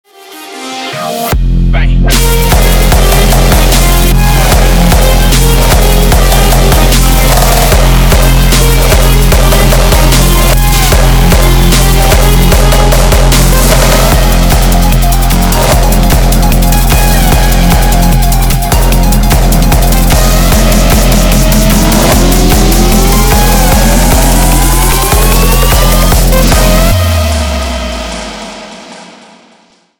hard trap